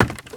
STEPS Wood, Creaky, Run 20.wav